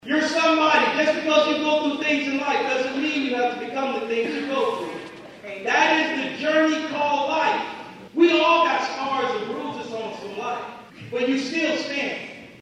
speaks to FHJCC graduates during the FHJCC graduation ceremony.